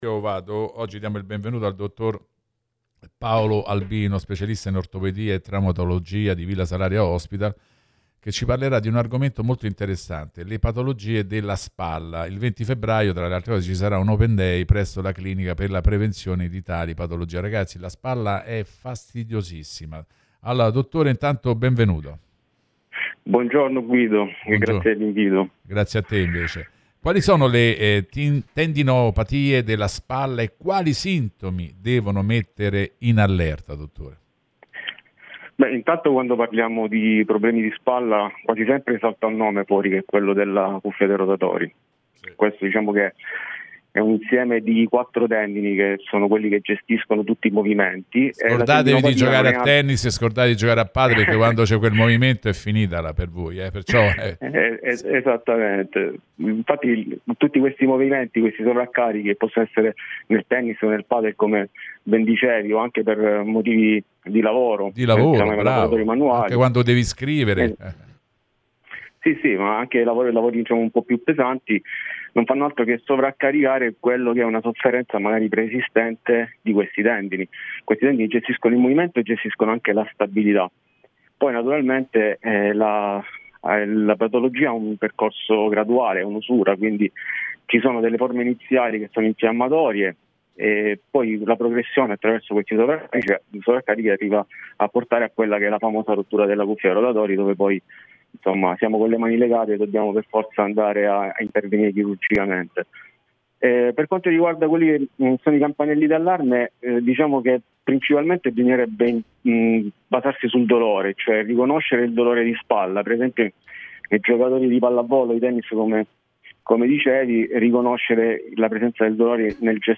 Intervista al dott.